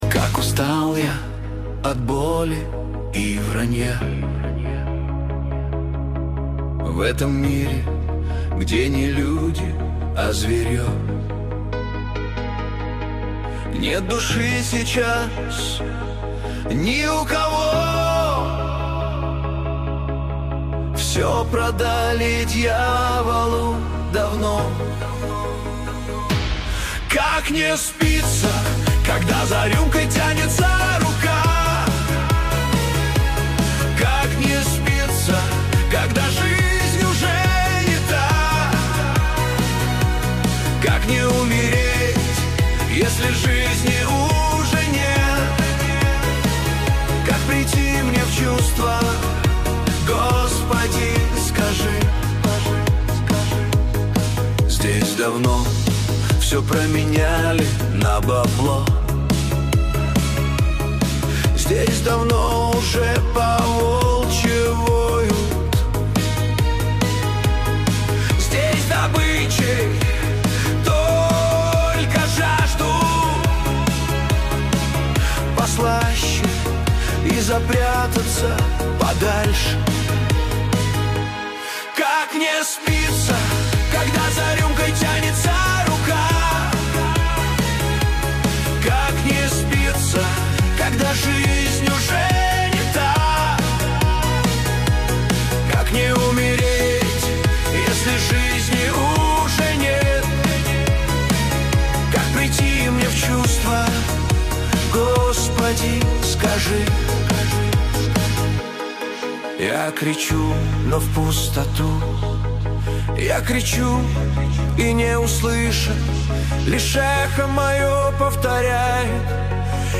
Качество: 128 kbps, stereo